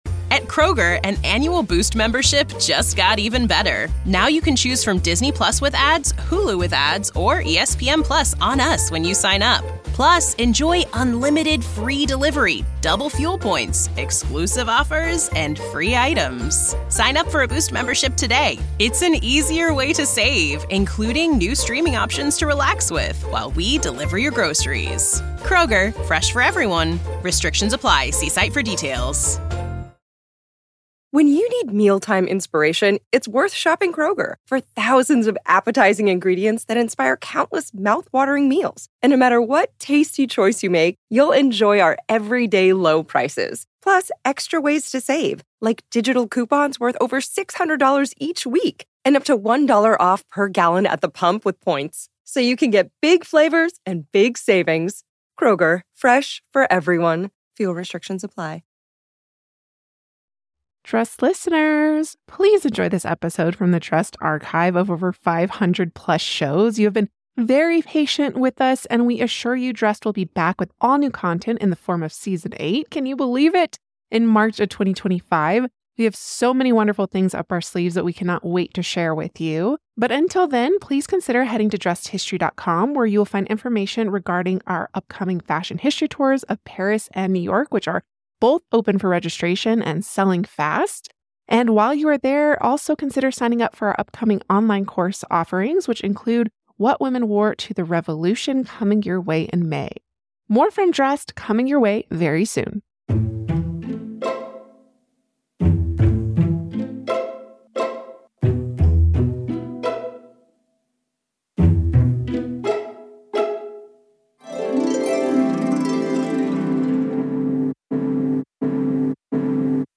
1 Lee Miller: Fashion in Wartime Britain, an interview